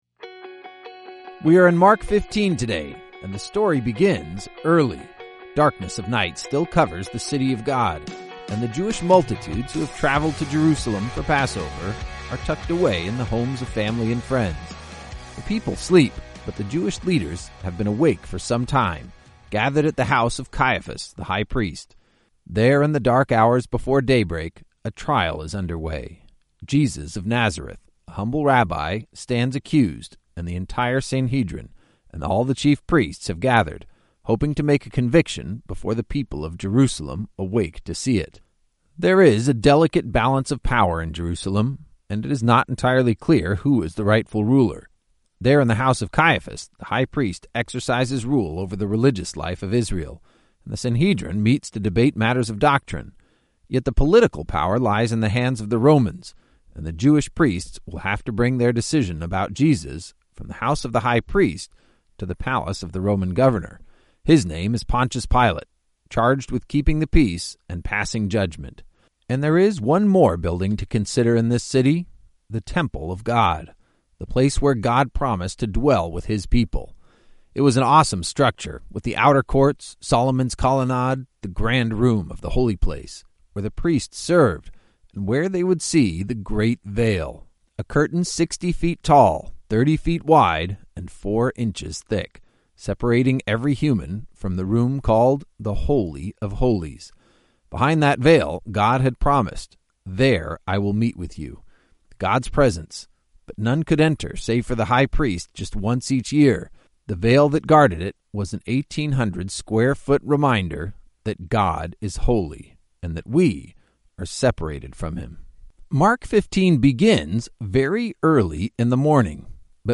Every chapter brings new insights and understanding as your favorite teachers explain the text and bring the stories to life.